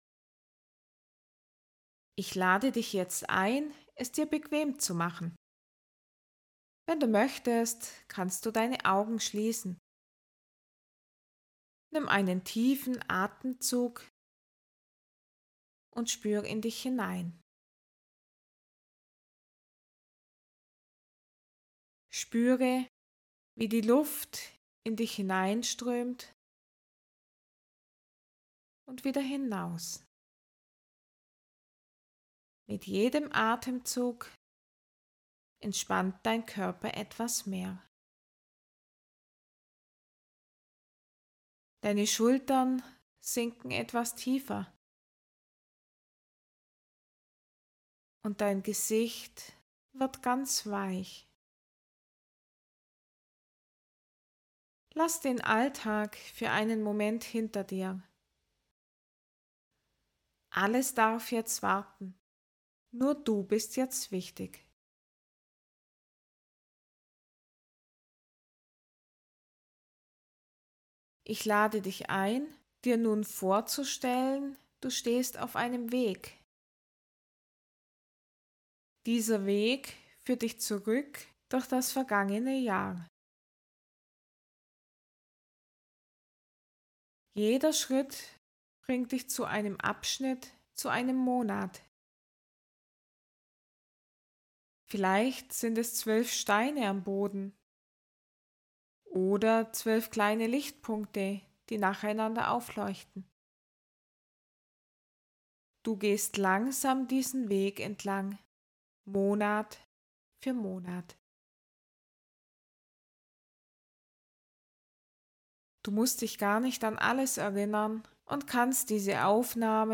Meditative_Hypnose_zum_Jahresrueckblick_neu.mp3